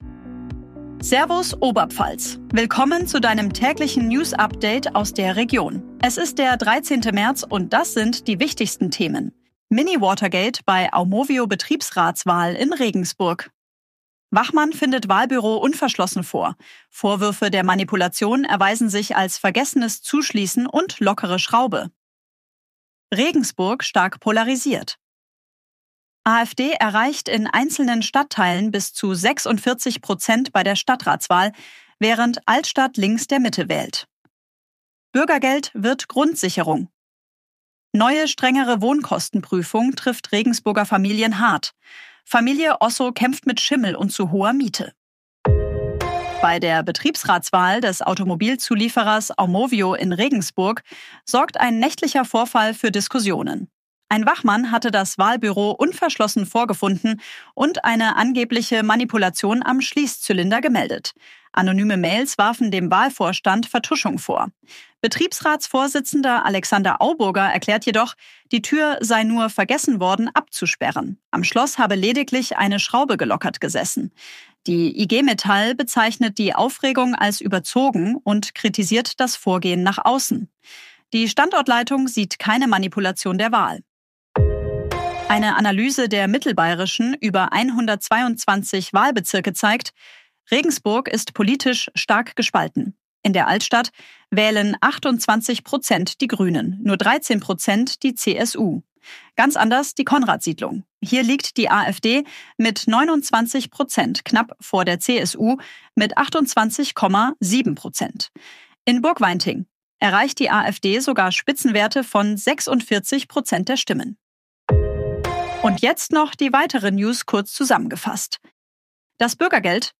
Tägliche Nachrichten aus deiner Region